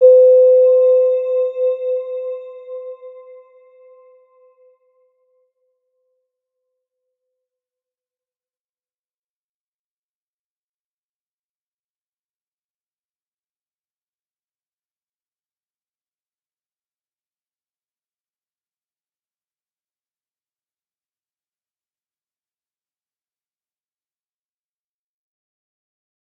Round-Bell-C5-f.wav